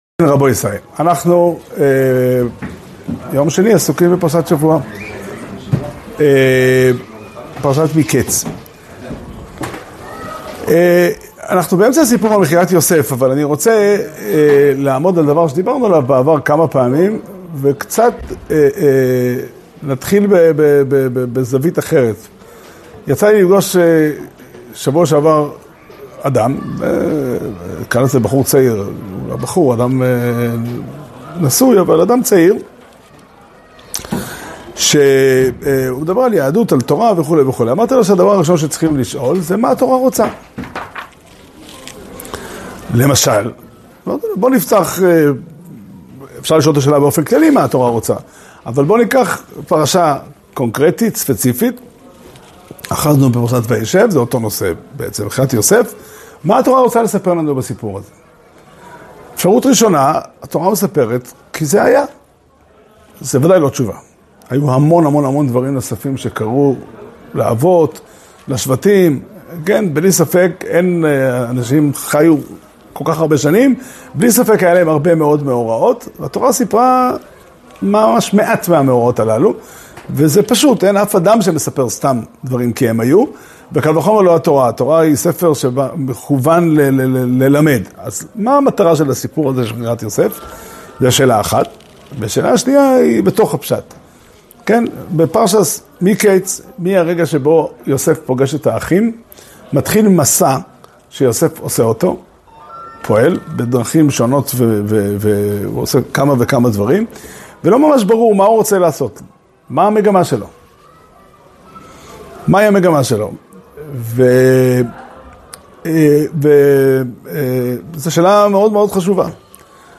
שיעור שנמסר בבית המדרש פתחי עולם בתאריך כ"ב כסלו תשפ"ה